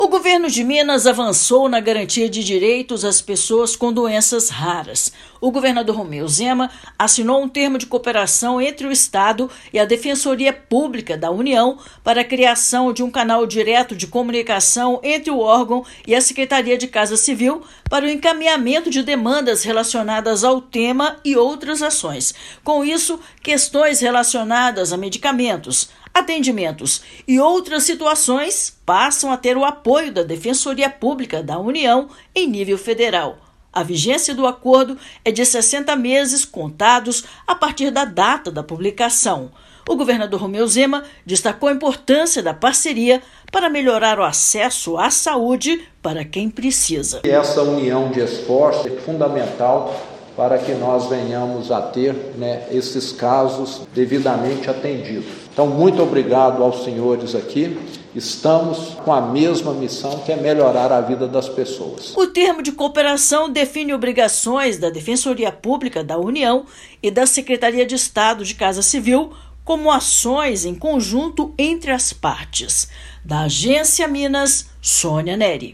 O objetivo é garantir acesso a medicamentos, atendimentos e outros serviços da esfera federal. Ouça matéria de rádio.